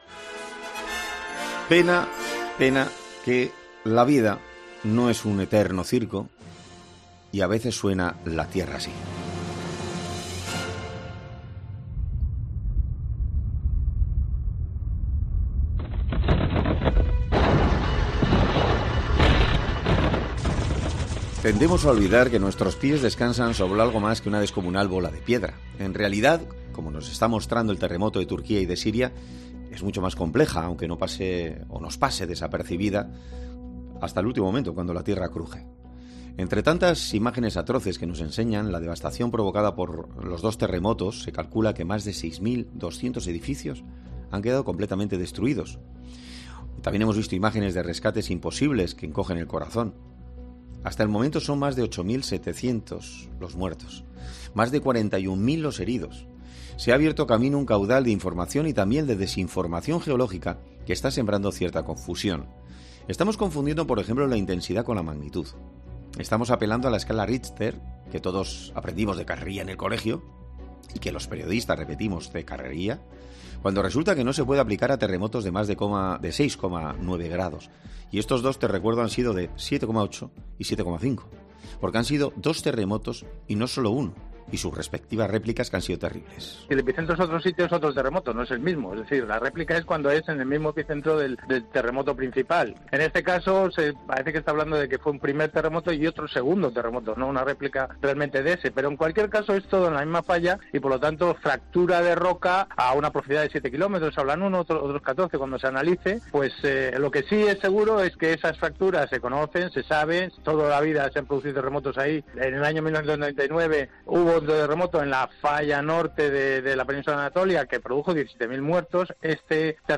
'Herrera en COPE' habla con un experto para aclarar y desmentir parte de la creencia popular de cómo se puede saber que va a haber un terremoto